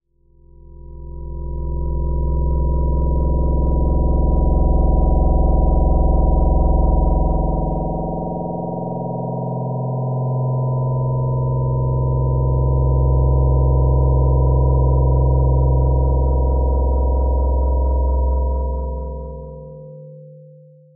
Spectral Drone 04.wav